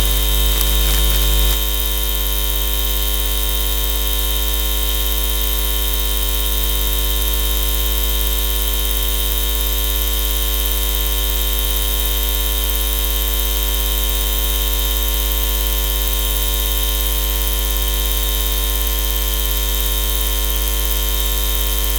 Look at this example, an oscilloscope via an aerial picking up the signal near a lighted led bulb and me talking next to it.
led-recording-via-aerial-24-oct-7-24-giving-voice-to-rf.mp3